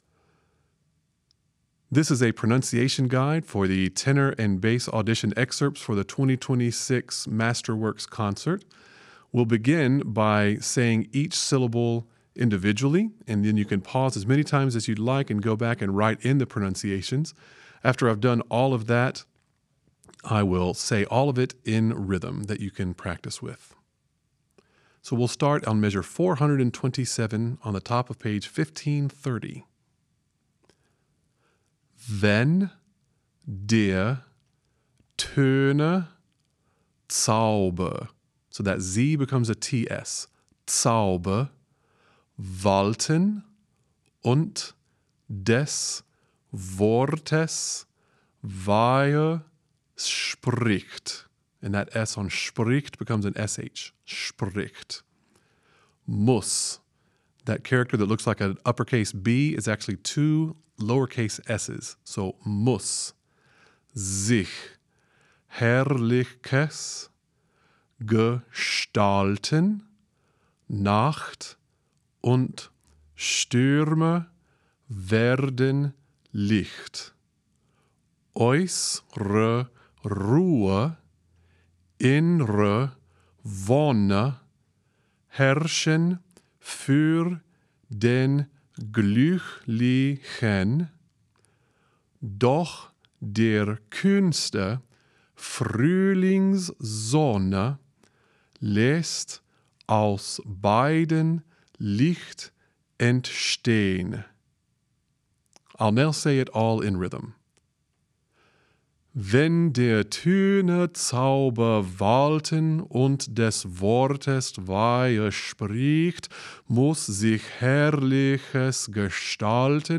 2)  Learn the German pronunciation with this pronunciation guide:
Tenor/Bass Pronunciation Guide